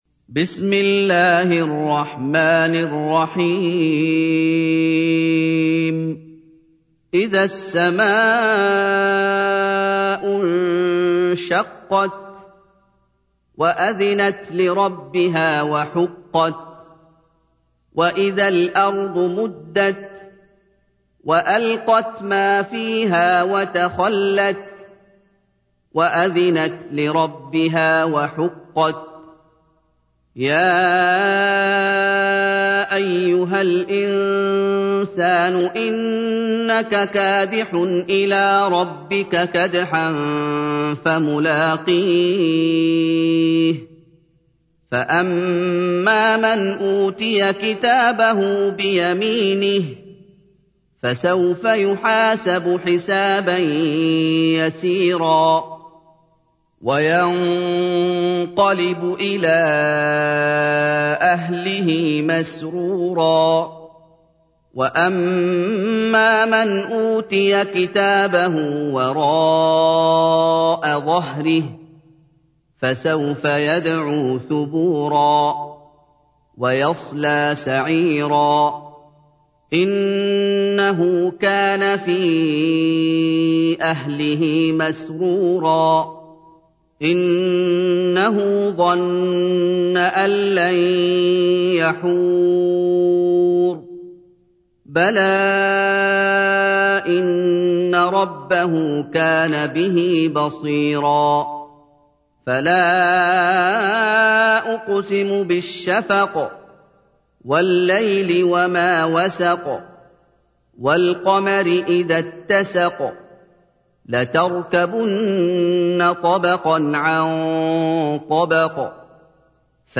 تحميل : 84. سورة الانشقاق / القارئ محمد أيوب / القرآن الكريم / موقع يا حسين